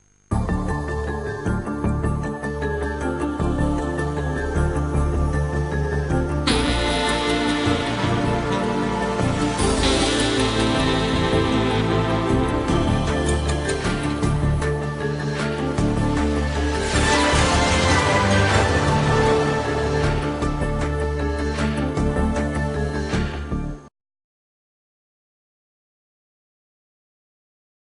Générique